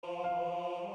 with an a capella
Voicing/Instrumentation: SATB